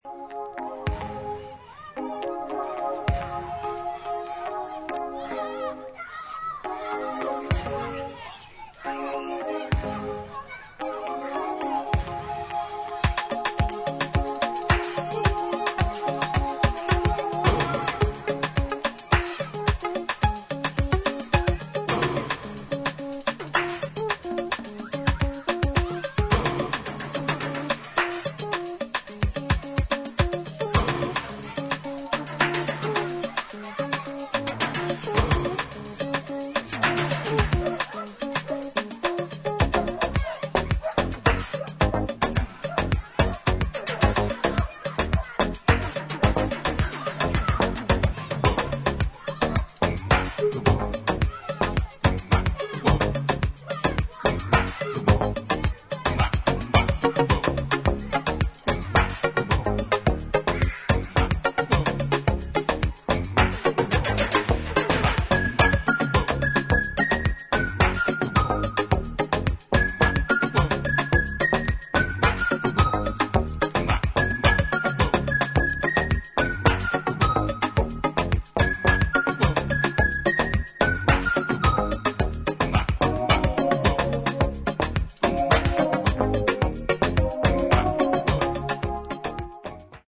軽やかでファンキーなグルーヴが抜群にノリの良いカリビアン・トラック